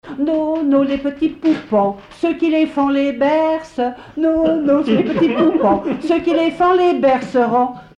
enfantine : berceuse
Catégorie Pièce musicale inédite